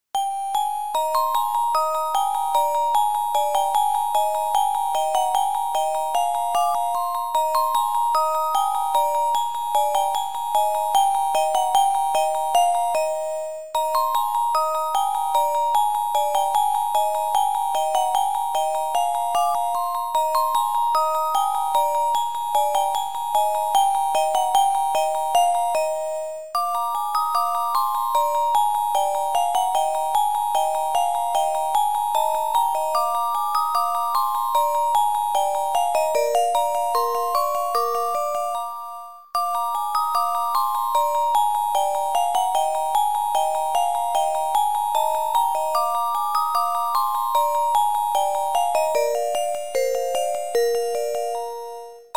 Sound Format: Noisetracker/Protracker
Sound Style: Chip